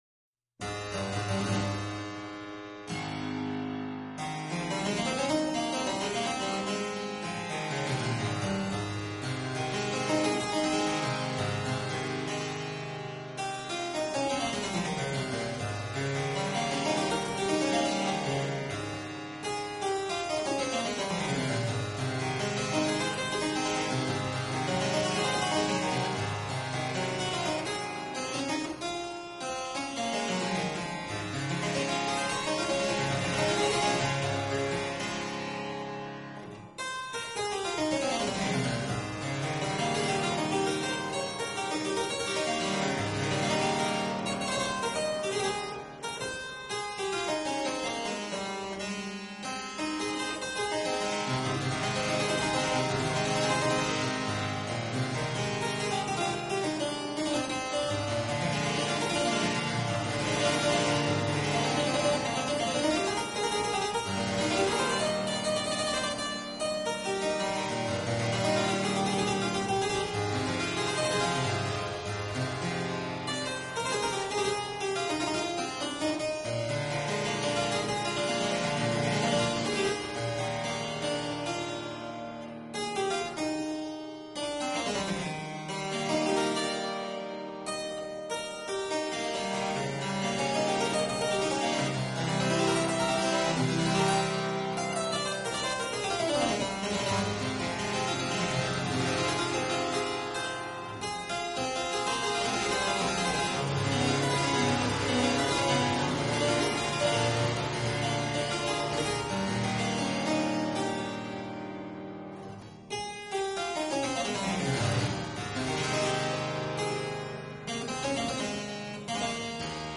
Harpsichord
Style: Classical